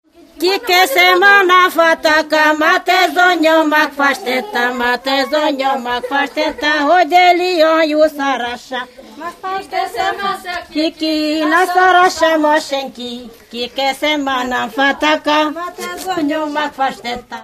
Moldva és Bukovina - Moldva - Alexandrina (Klézse)
Előadó: lakodalomra készülő szakácsasszonyok (sz. nincs), ének
Stílus: 7. Régies kisambitusú dallamok